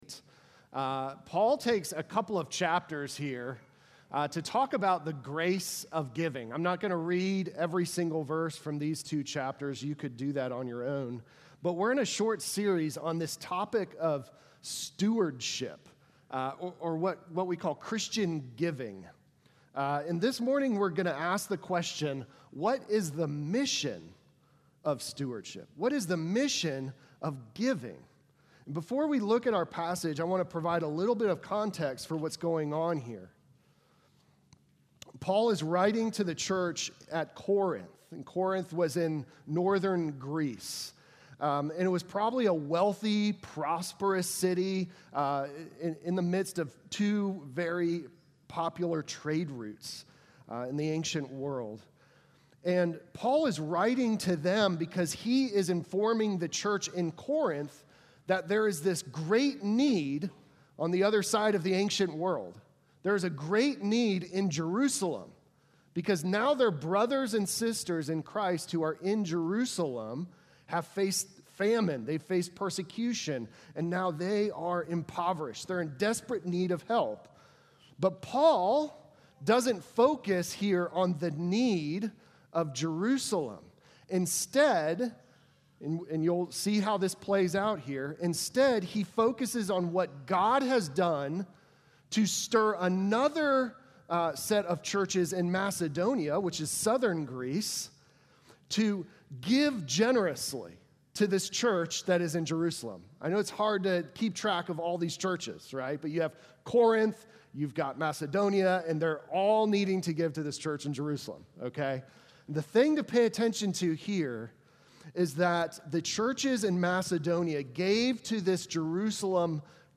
Sermon from July 6